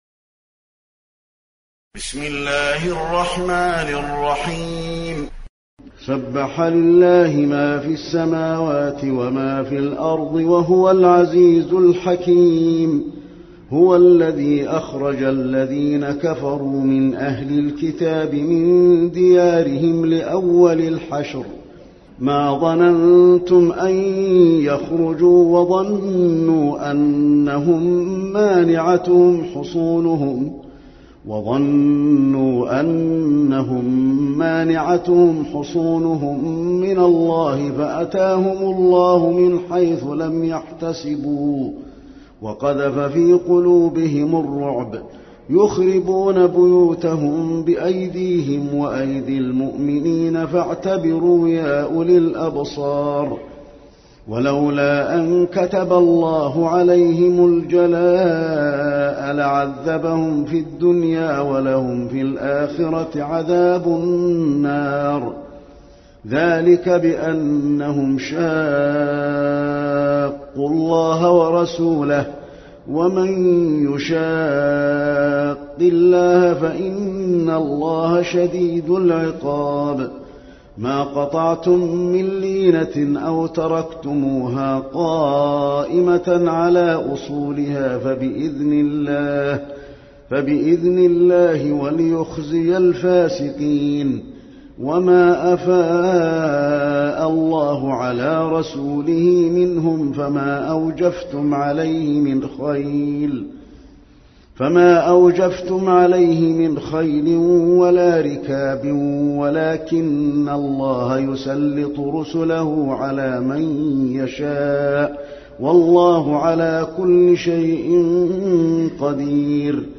المكان: المسجد النبوي الحشر